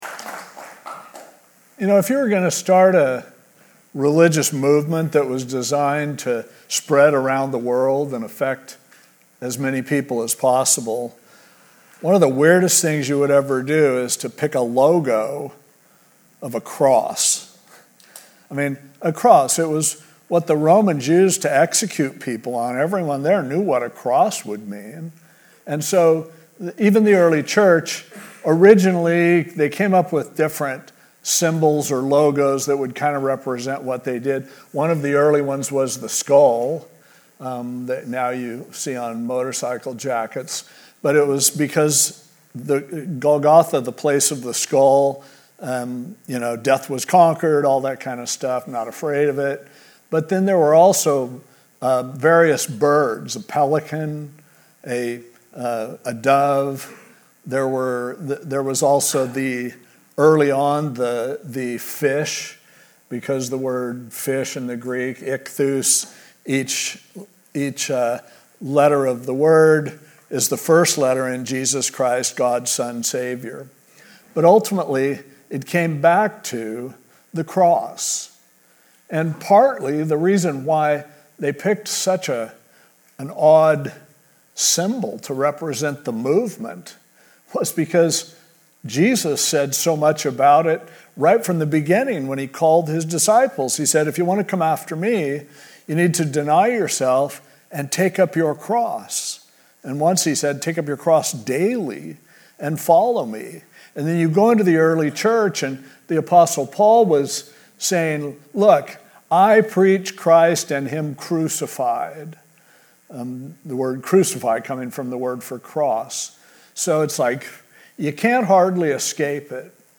Isaiah 53: Why A Cross? (Good Friday Special Message)